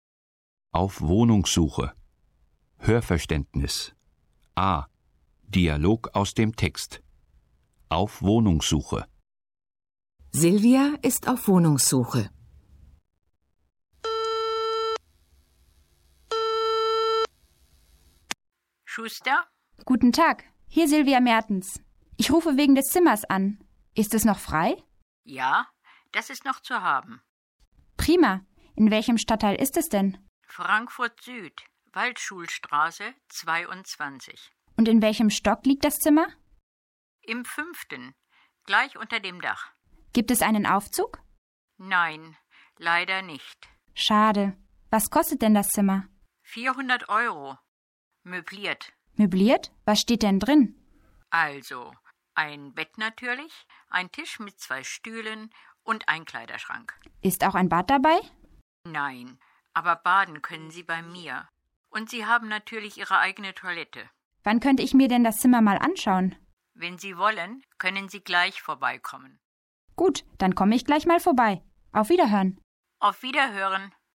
Dialog aus dem Text: Auf Wohnungssuche (1186.0K)